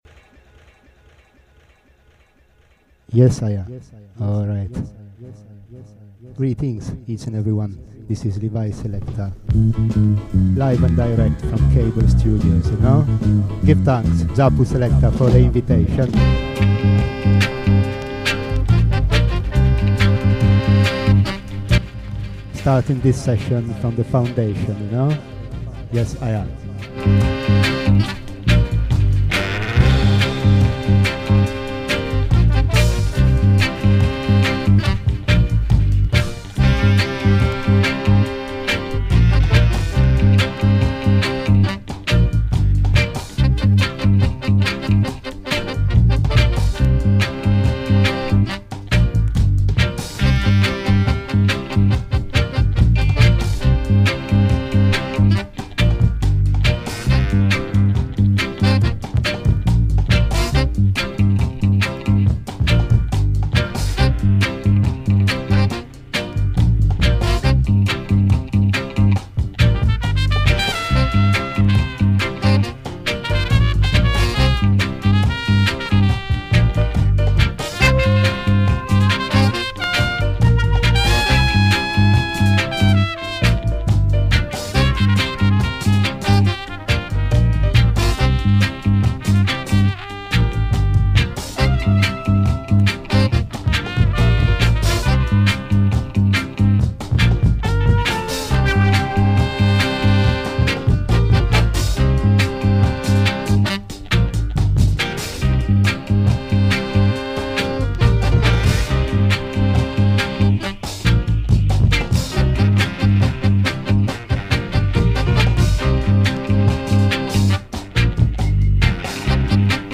Recorded live at Cable Studios (London / February 29th 2012)